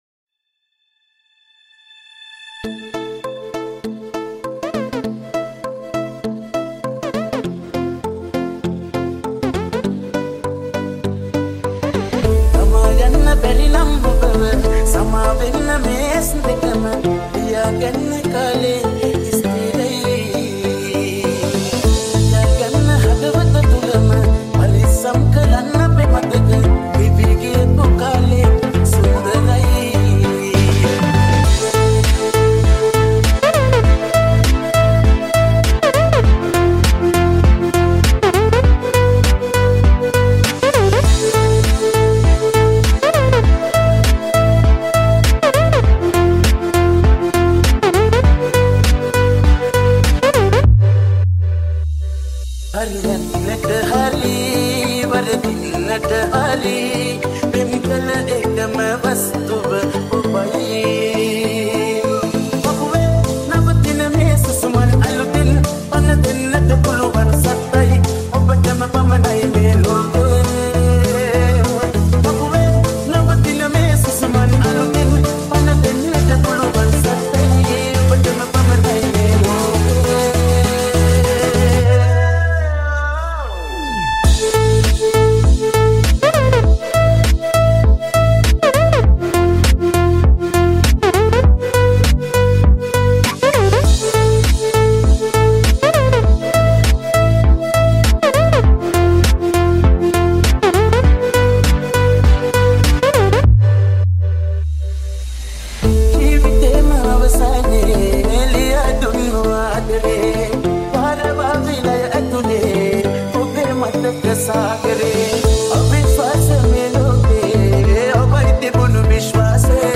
Sri Lankan remix